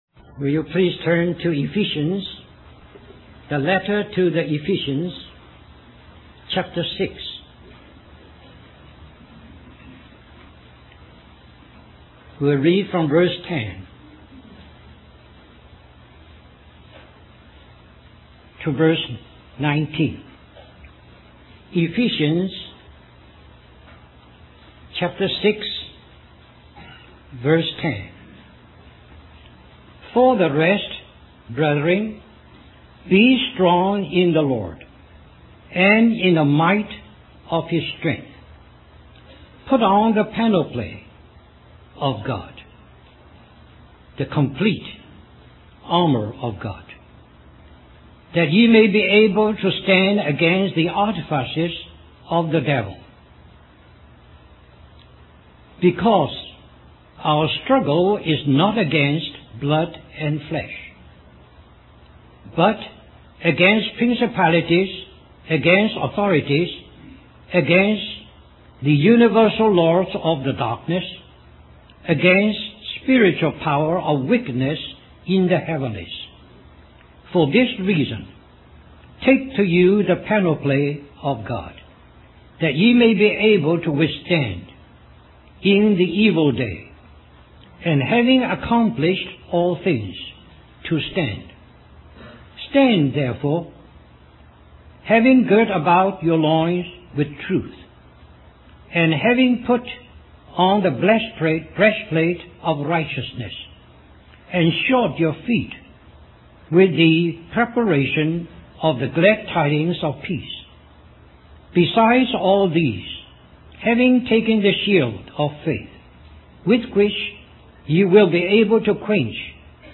A collection of Christ focused messages published by the Christian Testimony Ministry in Richmond, VA.
2001 Harvey Cedars Conference Stream or download mp3 Summary This message is also printed in booklet form under the title